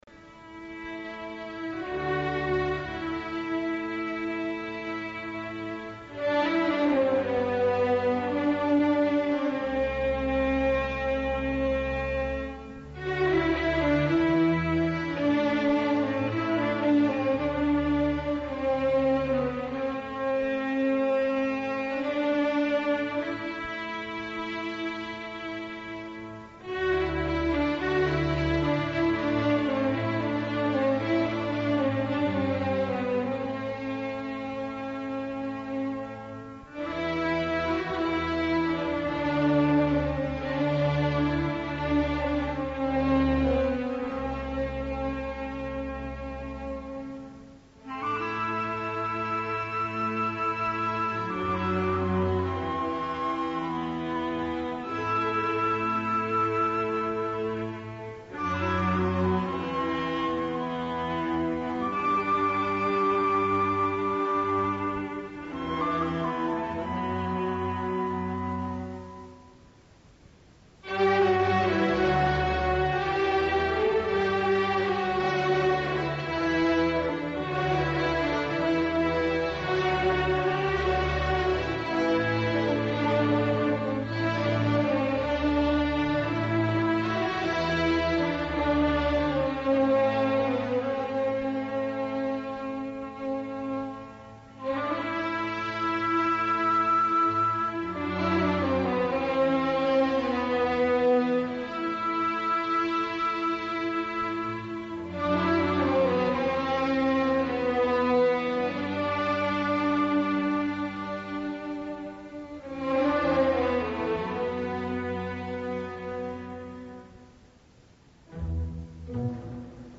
تصنیف